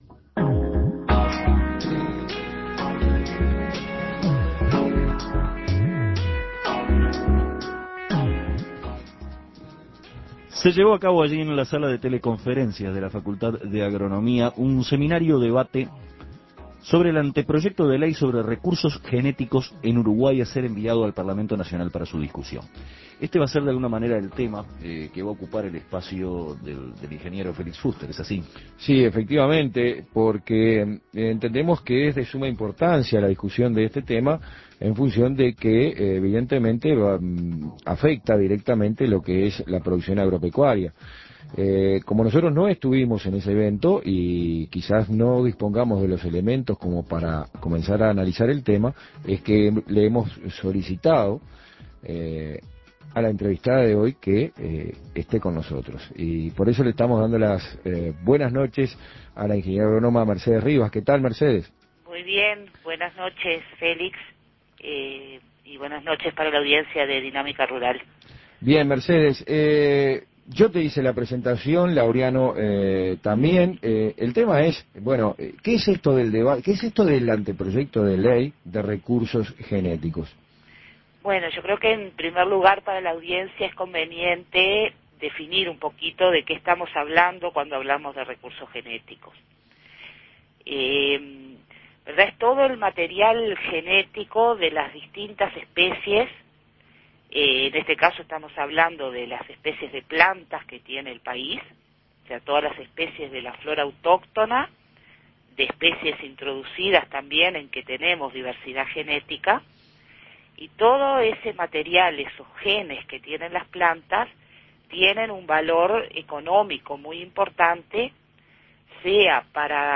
En el marco de un seminario realizado en la Facultad de Agronomía sobre el ante proyecto de ley sobre recursos genéticos en Uruguay